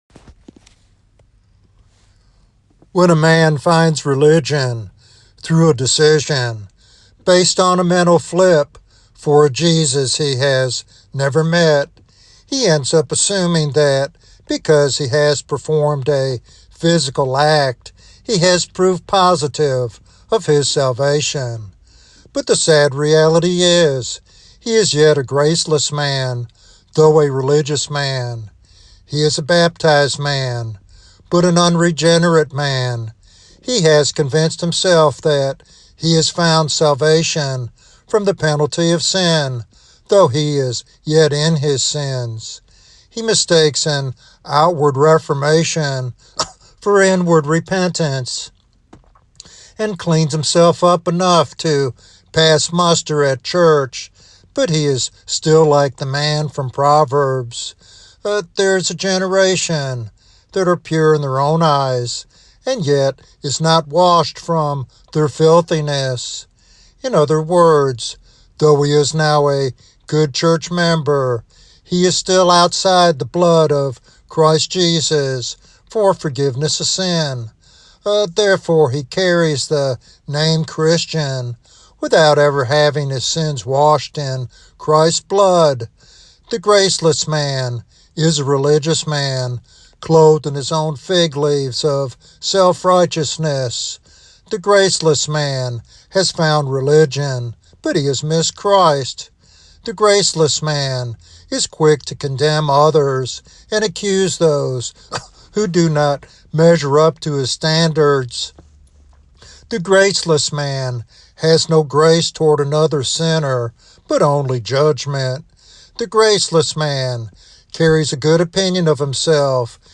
This sermon challenges believers to examine their hearts and embrace the transformative power of God's grace.